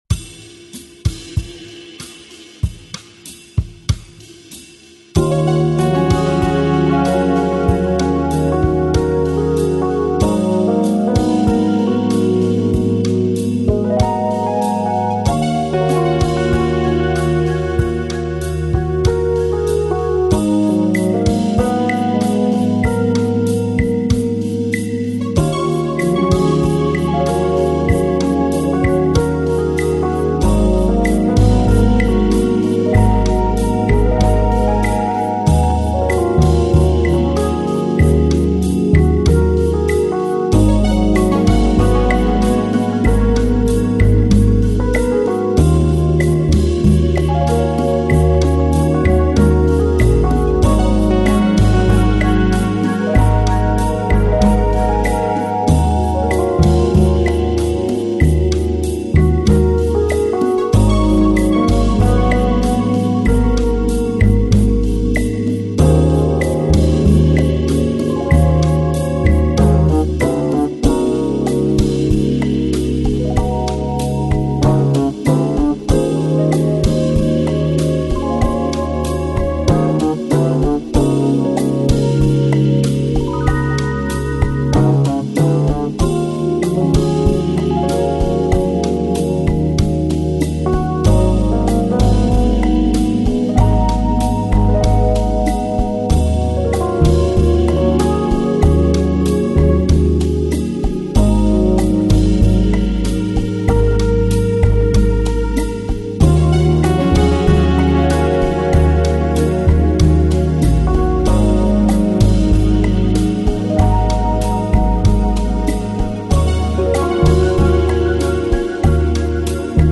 AAC Жанр: Chillout, Lounge, Trip-hop Продолжительность